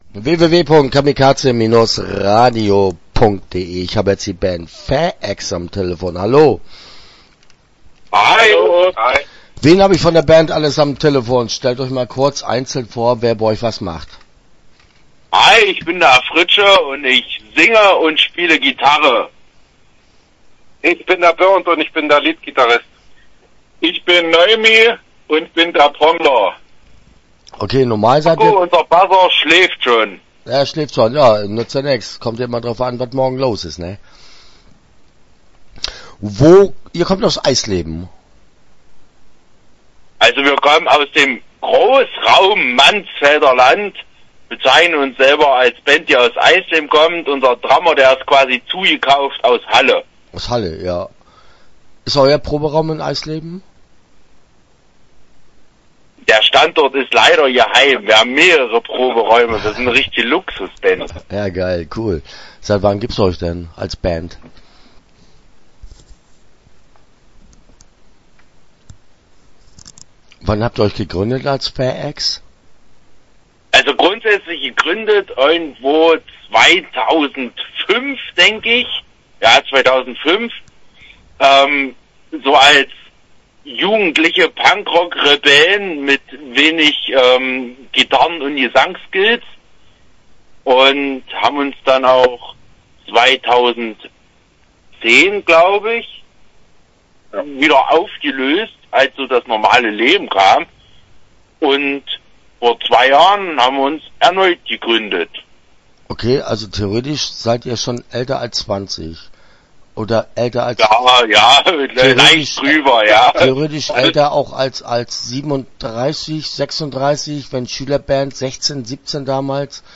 Start » Interviews » FÄEX